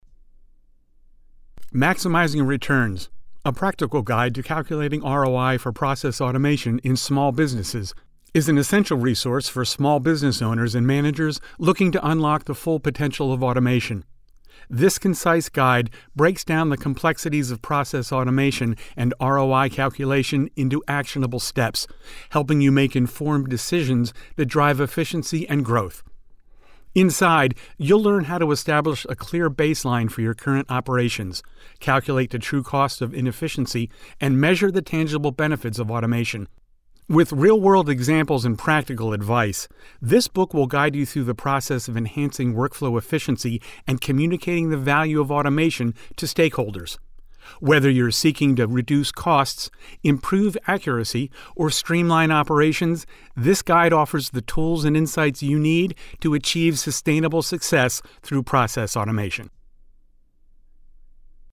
Audio Book - Business
English - USA and Canada
Middle Aged
Senior